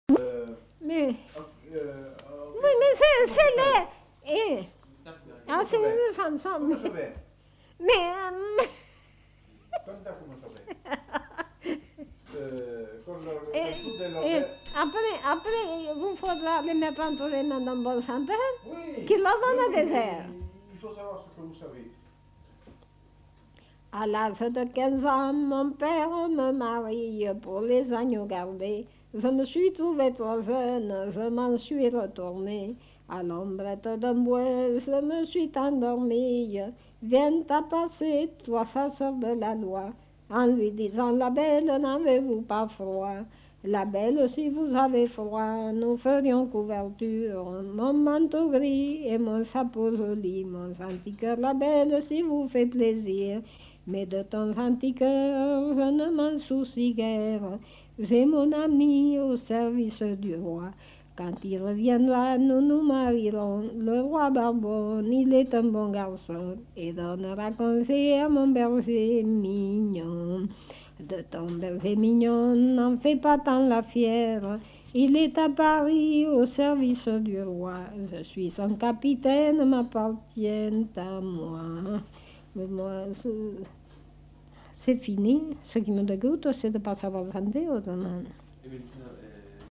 Aire culturelle : Périgord
Lieu : La Chapelle-Aubareil
Genre : chant
Effectif : 1
Type de voix : voix de femme
Production du son : chanté